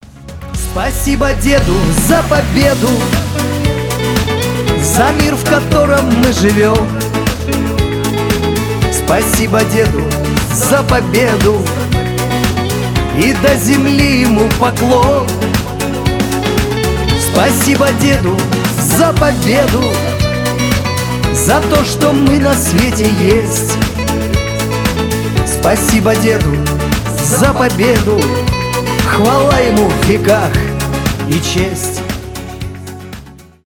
Категория: Шансон рингтоныРусские рингтоныРингтоны Военные